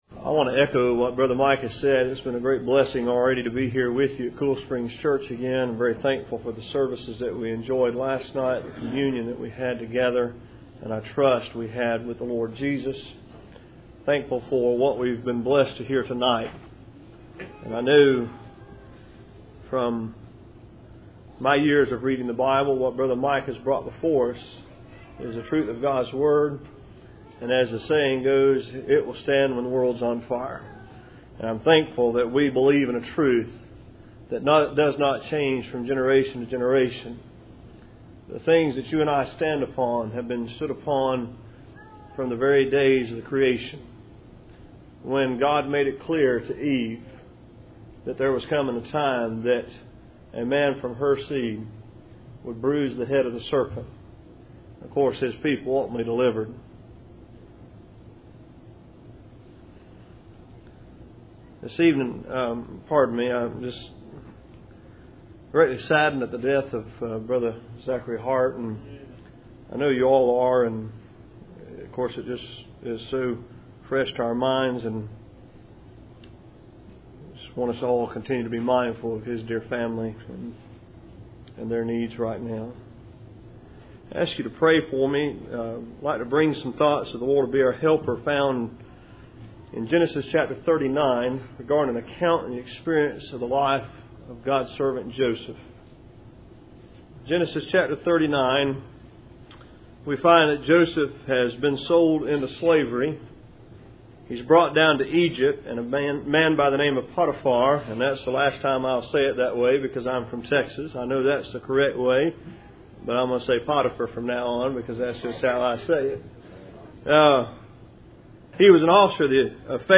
Passage: Genesis 39:12 Service Type: Cool Springs PBC August Annual Meeting %todo_render% « Communion Service at Cool Springs PBC